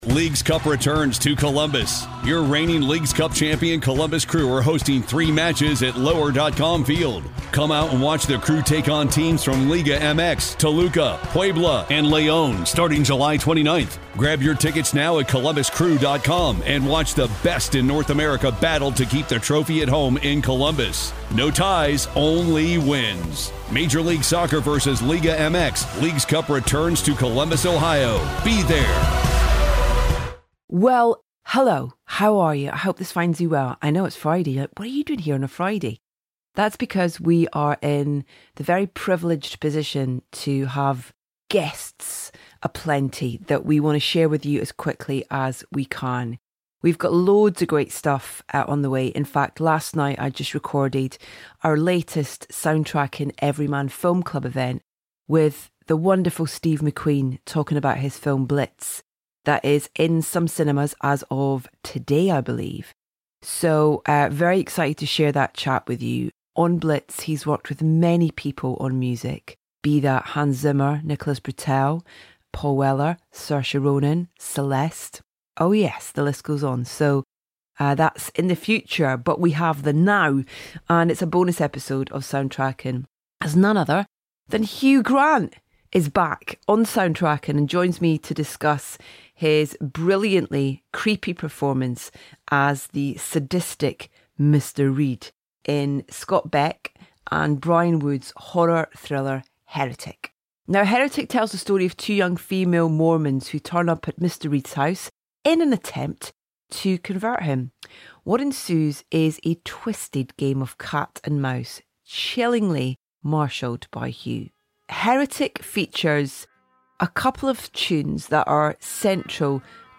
We have a bonus episode of Soundtracking for you today, as none other than Hugh Grant joins Edith to discuss his brilliantly creepy performance as the sadistic Mr Reed in Scott Beck and Bryan Woods's horror thriller, Heretic.